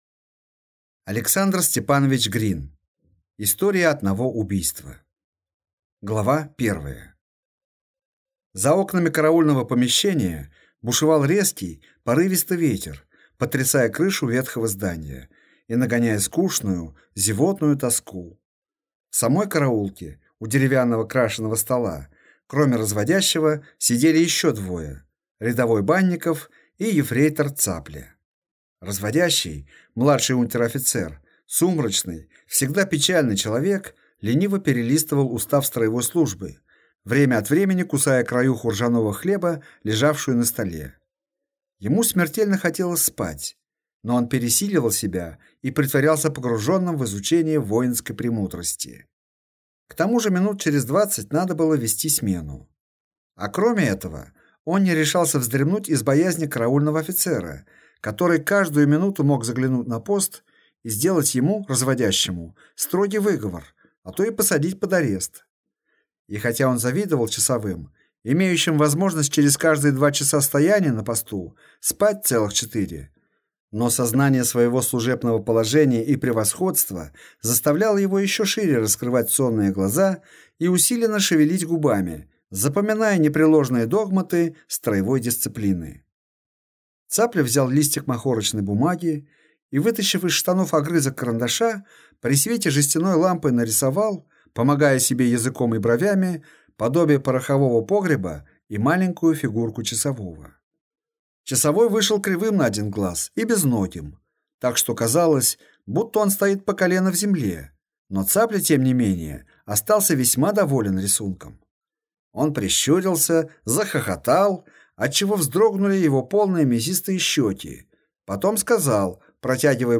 Аудиокнига История одного убийства | Библиотека аудиокниг